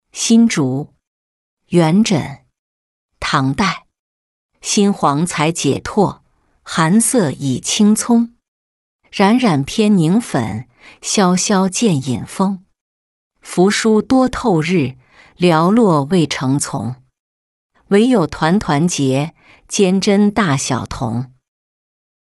新竹-音频朗读